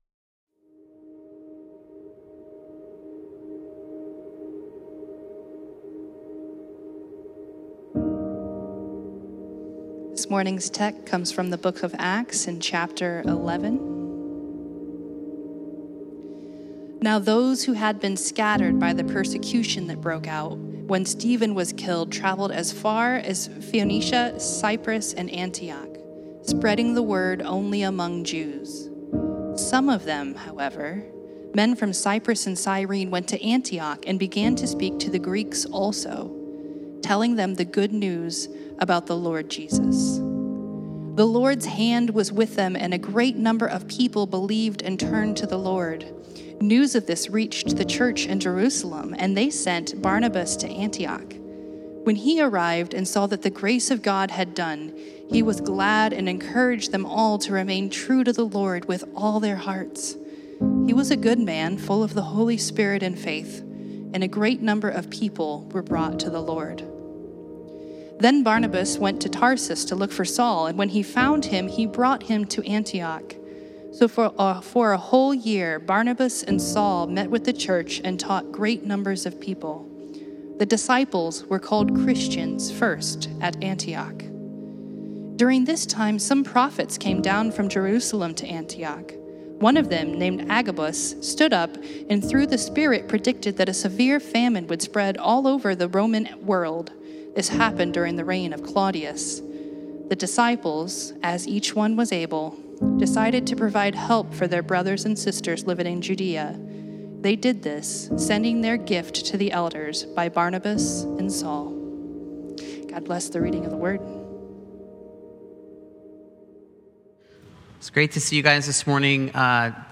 Sermons | Central Vineyard Church